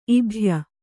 ♪ ibhya